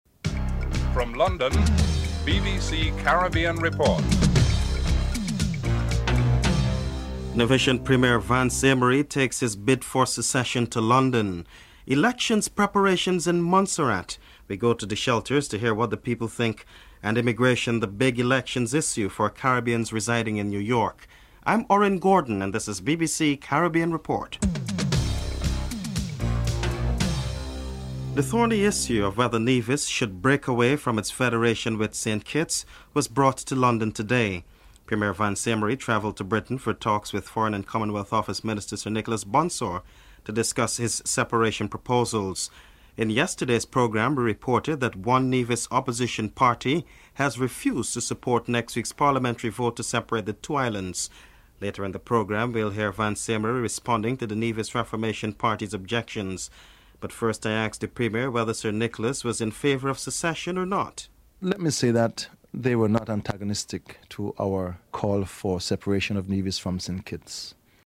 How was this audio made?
3. Election preparations in Montserrat - we go to the shelters to hear what the people think.